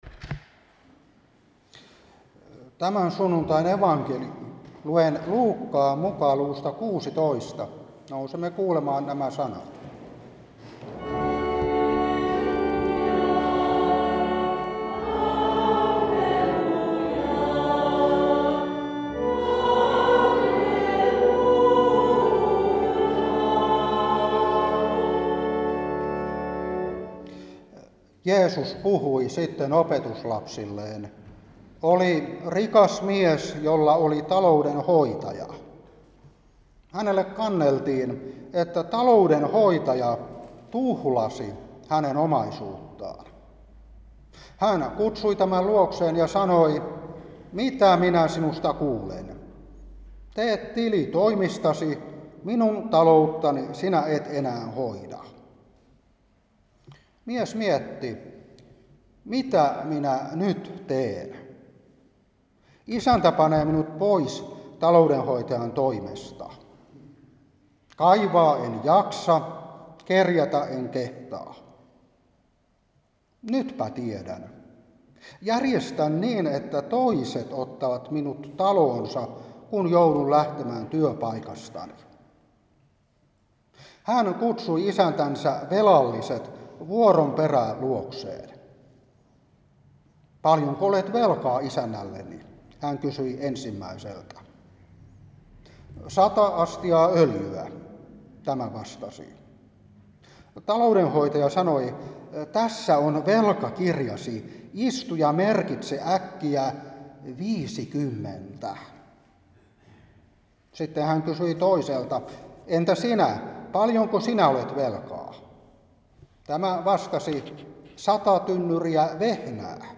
Saarna 2025-8.